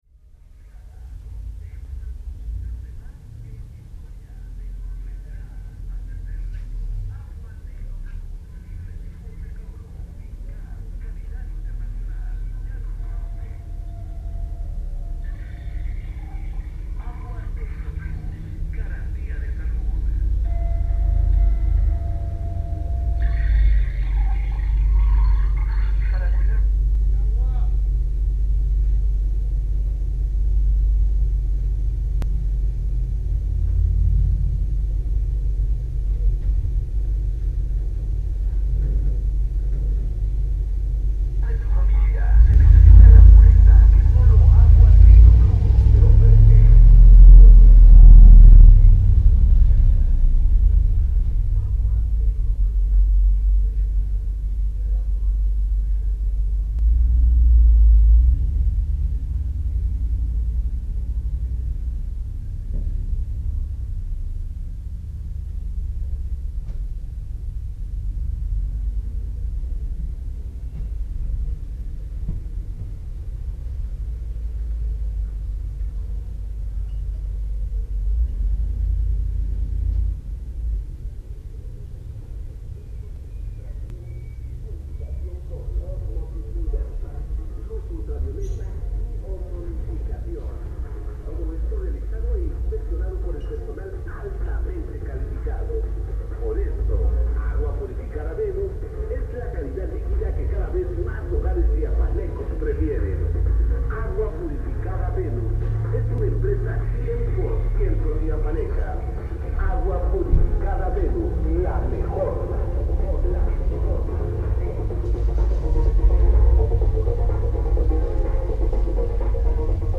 Lugar: Tuxtla Gutierrez, Chiapas; Mexico.
Equipo: Minidisc NetMD MD-N707, micrófono de construcción casera ( más info ) Fecha: 2009-01-11 07:42:00 Regresar al índice principal | Acerca de Archivosonoro